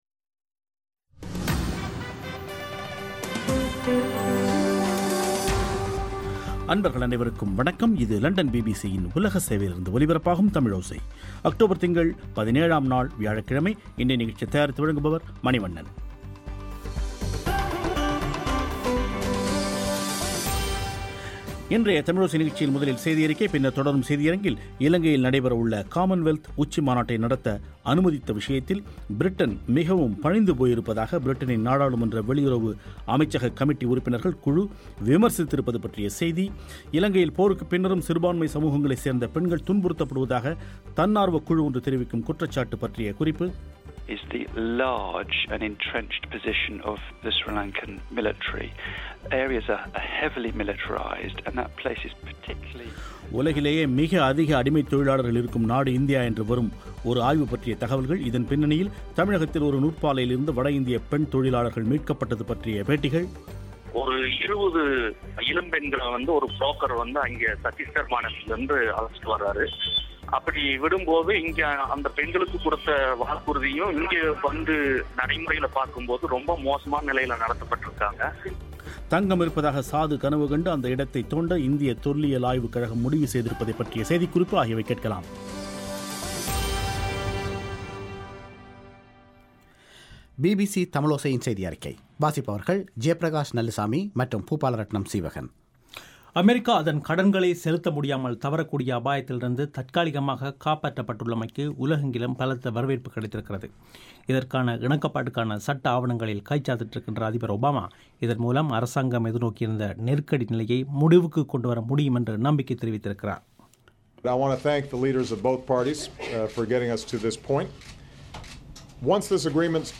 இதன் பின்னணியில் தமிழகத்தில் ஒரு நூற்பாலையிலிருந்து வட இந்திய பெண் தொழிலாளர்கள் மீட்கப்பட்ட்து பற்றிய இரு பேட்டிகள்